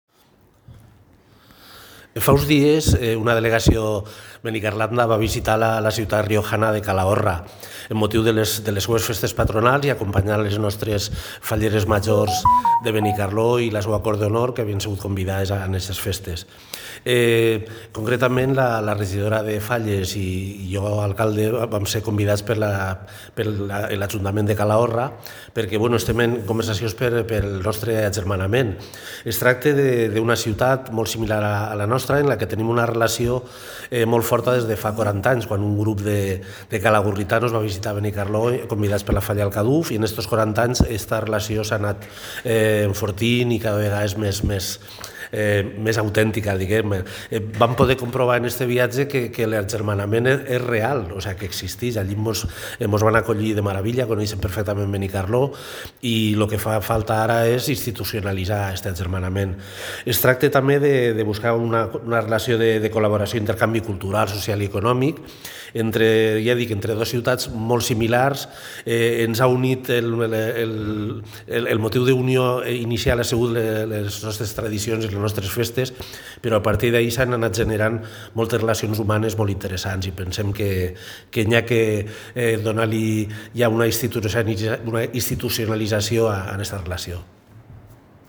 alcalde_agermanament_calahorra.mp3